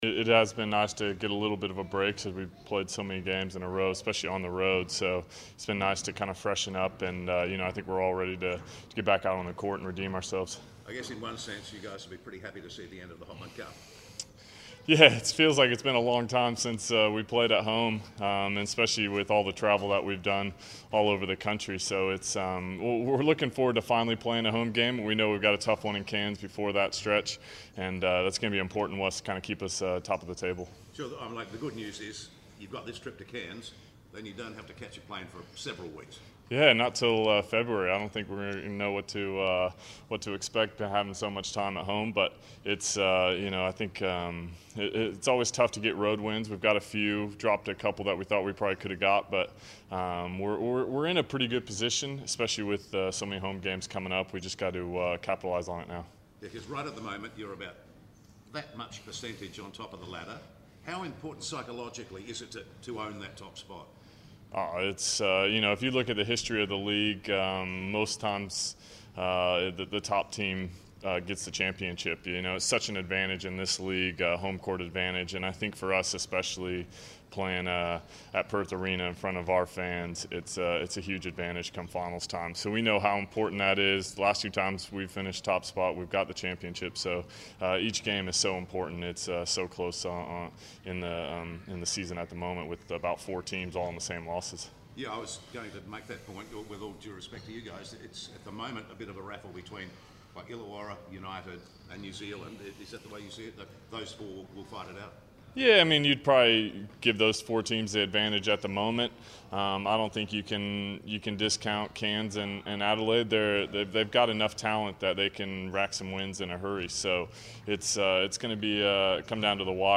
Shawn Redhage press conference - 4 January 2015
Shawn Redhage speaks to the media ahead of the Perth Wildcats clash versus the Cairns Taipans on Friday.